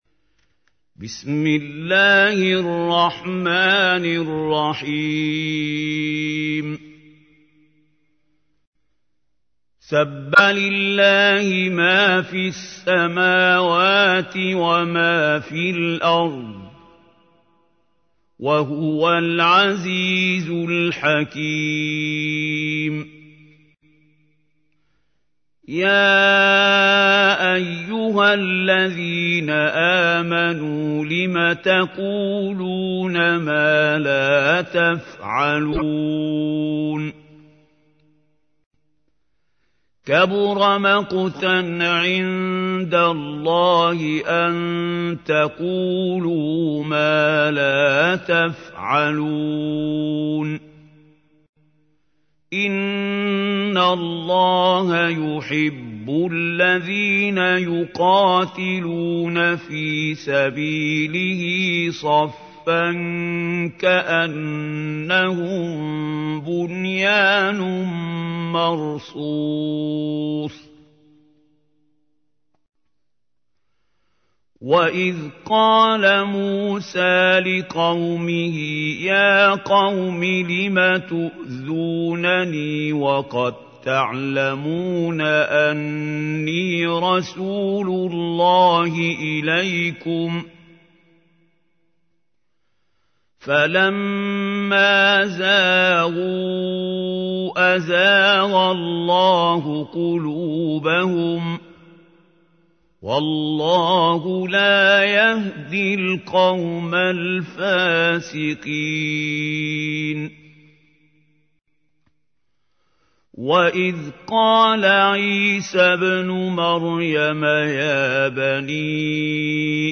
تحميل : 61. سورة الصف / القارئ محمود خليل الحصري / القرآن الكريم / موقع يا حسين